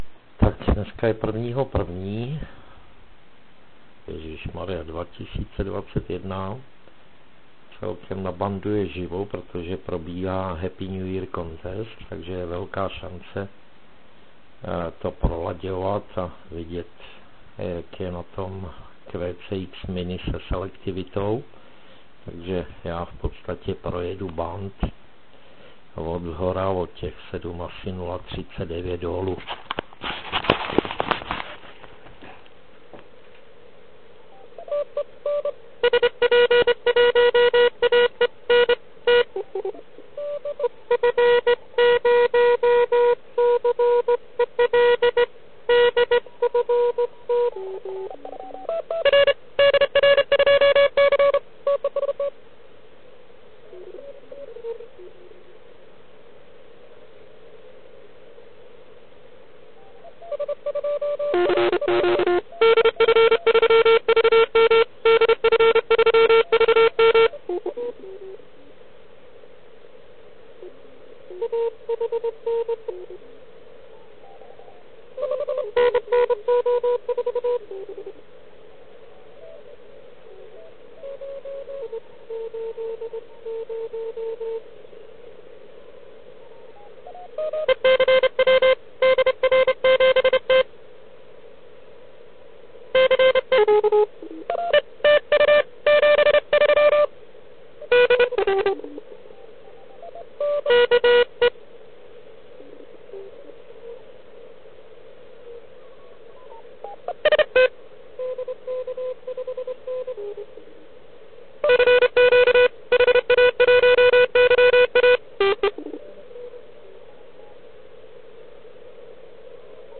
Poslechové zkoušky QCX mini při HNY contestu
Jak už jsem říkal, provedl jsem úpravu NF filtru a přeladil jsem ho cca na 500 Hz. Měl by mít šířku cca 200Hz (asi na 6dB).
Test 500Hz QCX mini.mp3
QCXmini500Hz.mp3